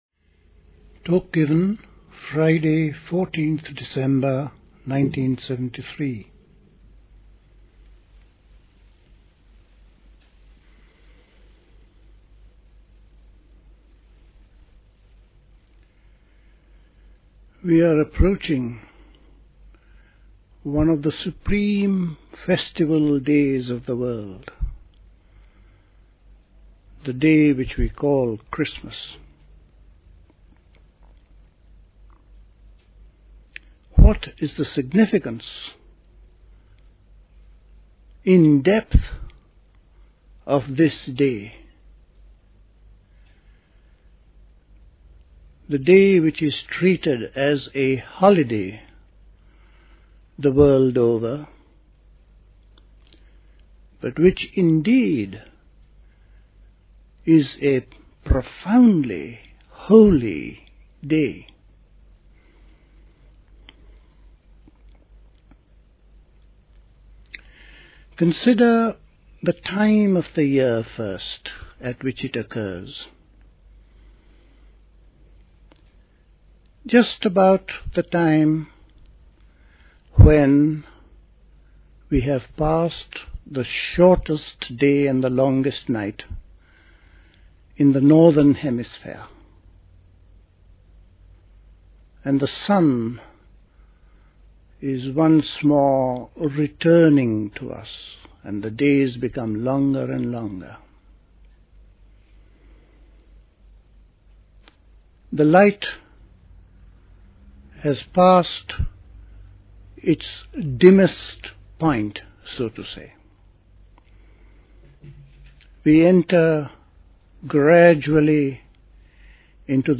Religiousness: Implications of Sīla and Samādhi Talk